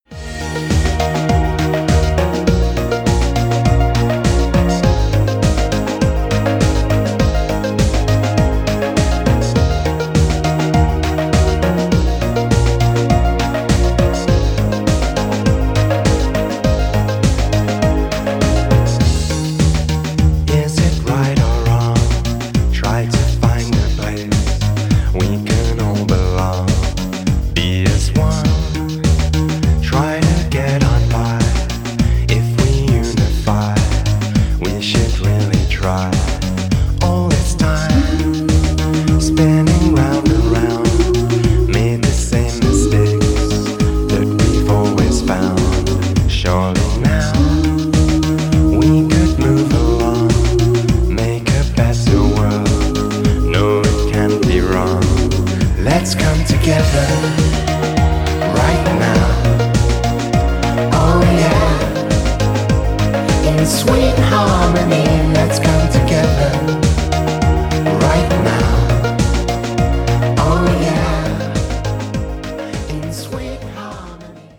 • Качество: 256, Stereo
поп
спокойные